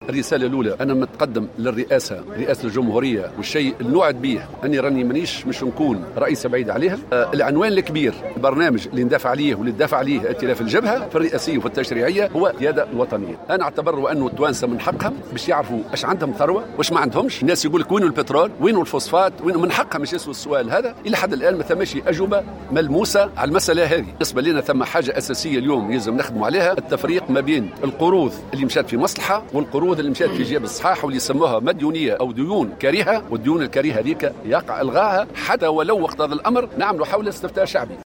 Dans une déclaration accordée à Jawhara FM, le candidat de la coalition du Front populaire à la présidentielle anticipée Hamma Hammami a assuré que le but de sa candidature est de défendre la souveraineté nationale.